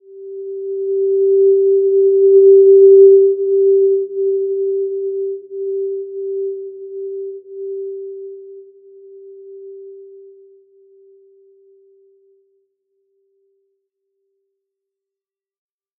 Simple-Glow-G4-mf.wav